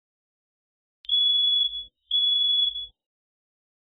Alarm Beep
Alarm Beep is a free ui/ux sound effect available for download in MP3 format.
313_alarm_beep.mp3